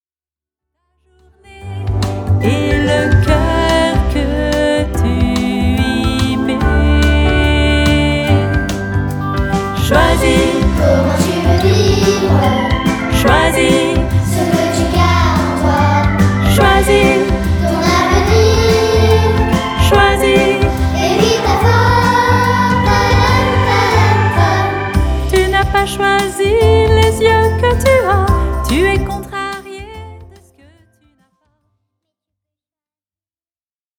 10 chants rythmés et enjoués